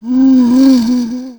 c_zombim5_hit3.wav